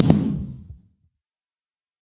largeBlast1.ogg